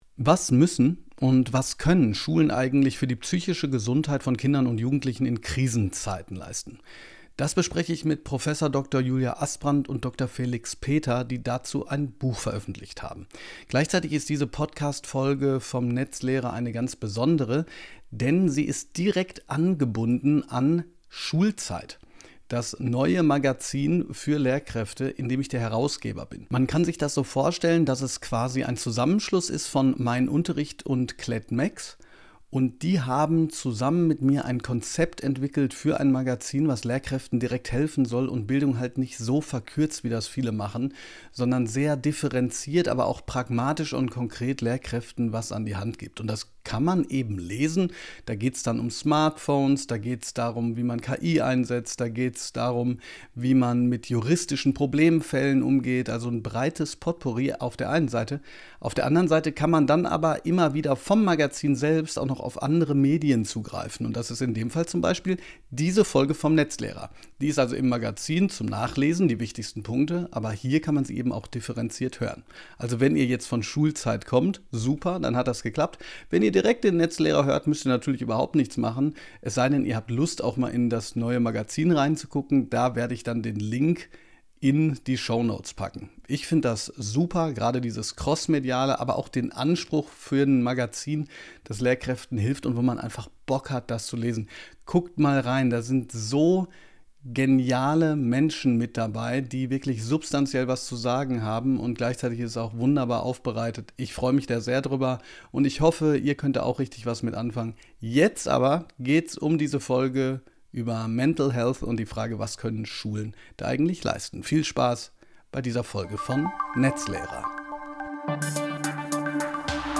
Beschreibung vor 1 Monat In dieser Folge habe ich mal wieder Gäste